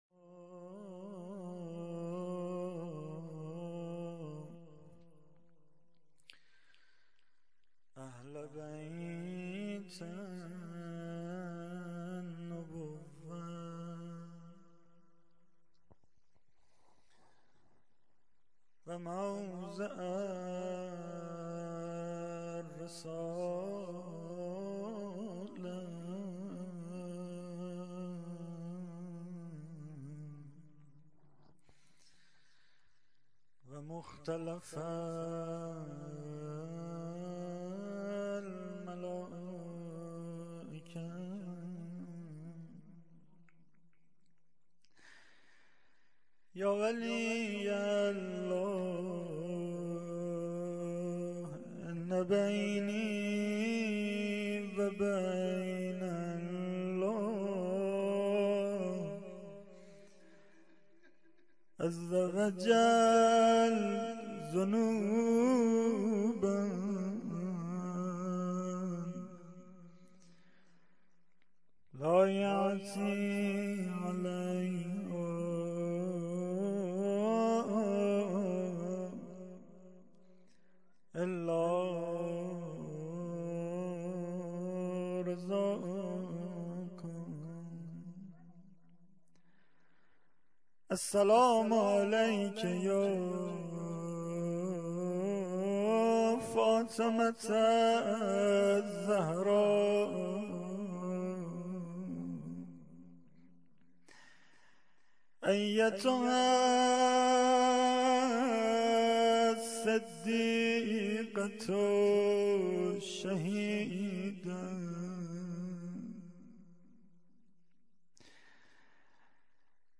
rozeh.mp3